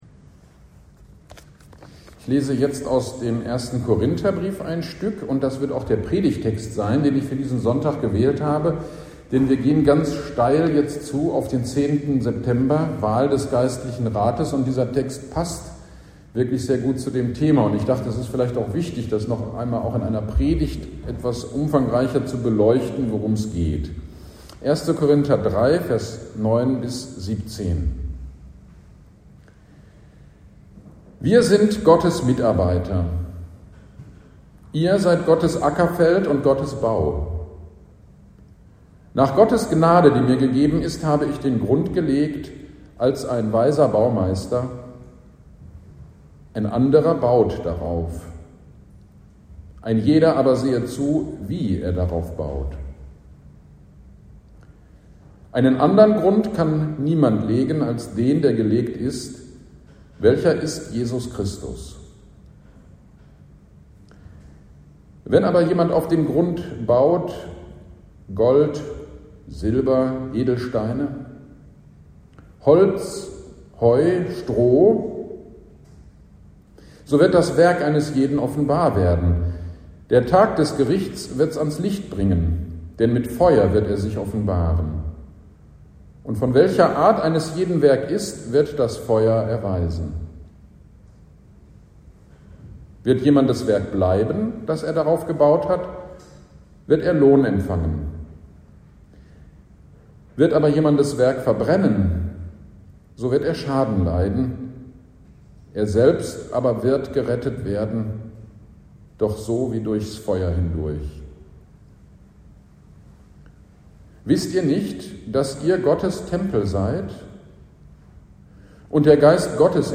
GD am 27.08.23 Predigt zu 1. Korinther 3.9-17 - Kirchgemeinde Pölzig